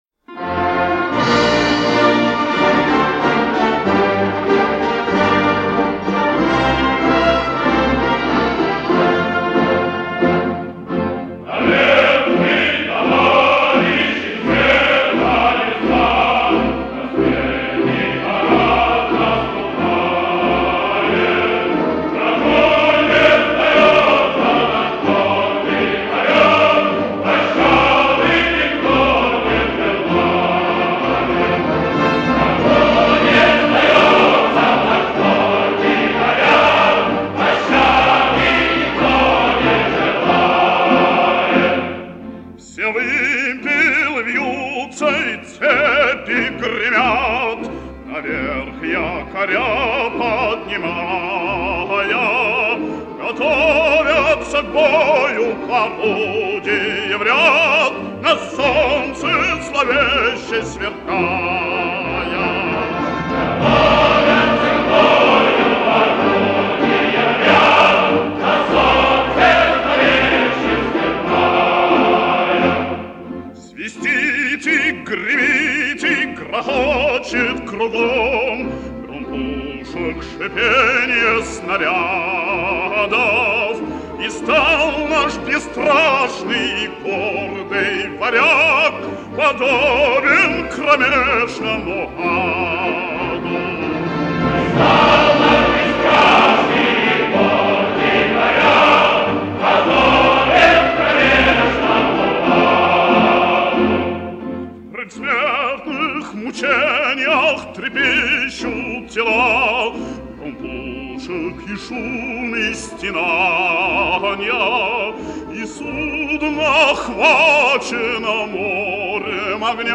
Всем известна песня моряков ВМФ в мощном исполнении.